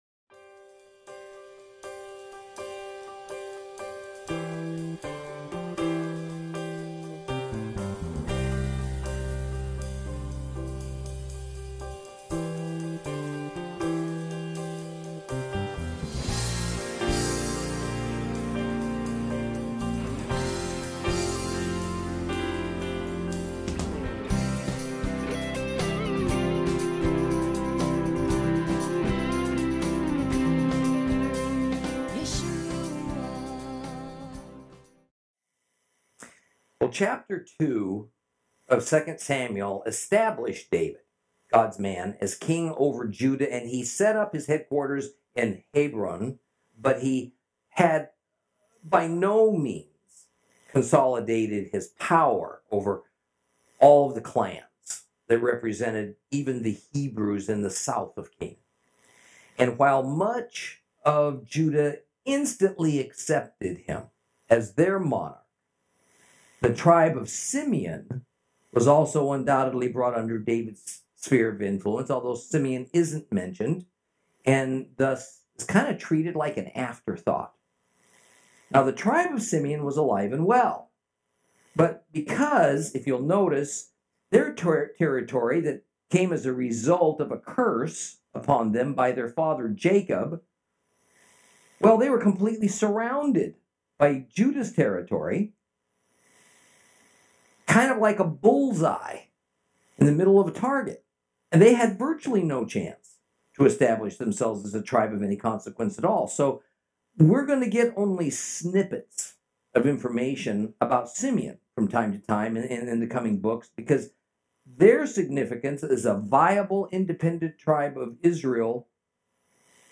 Lesson 4 Ch3 - Torah Class